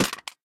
main Divergent / mods / Soundscape Overhaul / gamedata / sounds / material / small-weapon / collide / hithard01hl.ogg 7.5 KiB (Stored with Git LFS) Raw Permalink History
hithard01hl.ogg